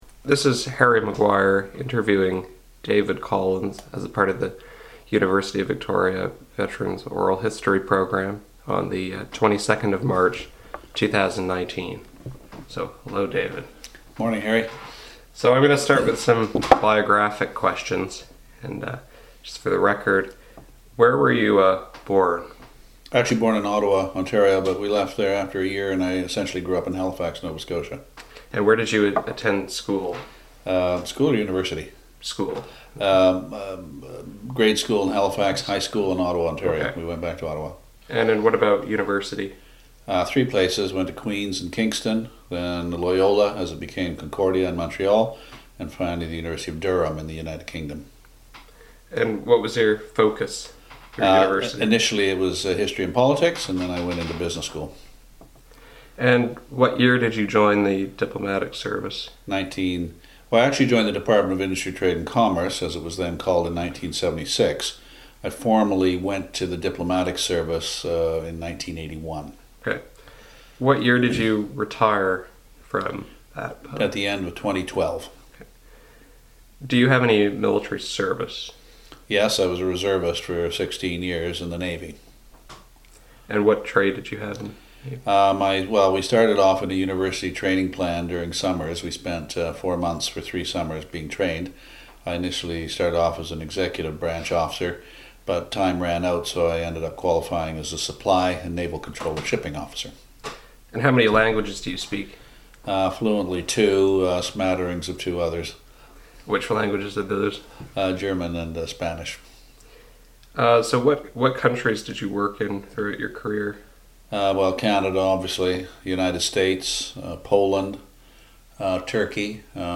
Interview took place on March 22, 2019 in Victoria, B.C.